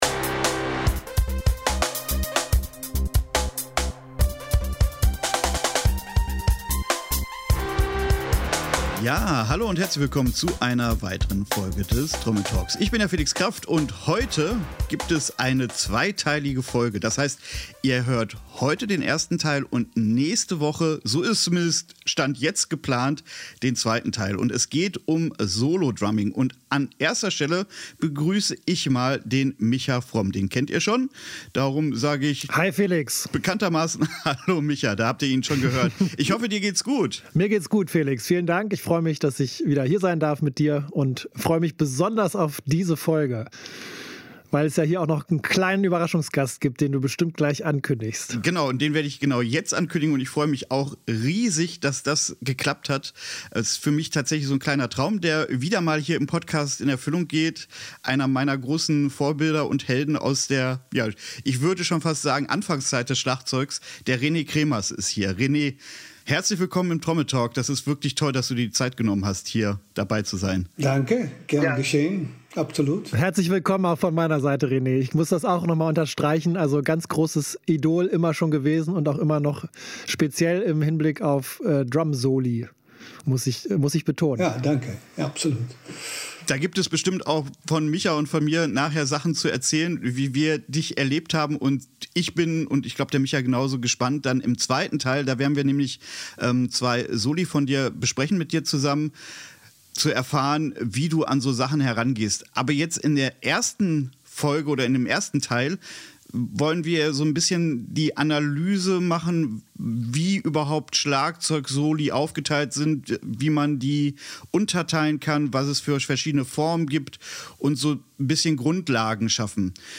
Aber nicht einfach irgendein Interview, sondern zu dem, was einen großen Teil seiner Schlagzeugkunst ausmacht: Das Solo Spiel.